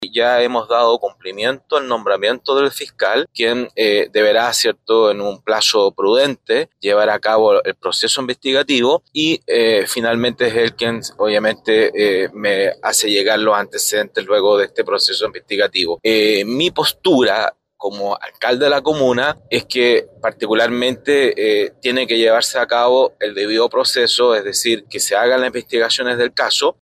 En el caso de San Juan de la Costa, el alcalde, José Luis Muñoz, explicó que la Contraloría entregó instrucciones precisas respecto a los cursos de acción, por lo que ya se inició el proceso de sumario administrativo, donde se detectó a 21 funcionarios involucrados.